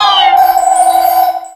Cri de Cresselia dans Pokémon X et Y.